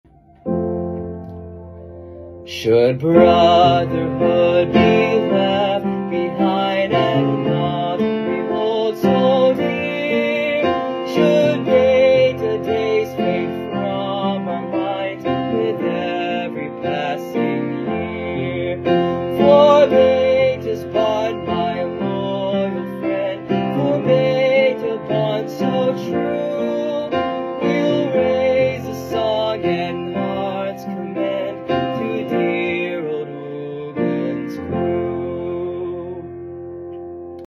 Singing Awards
Best New Song Competition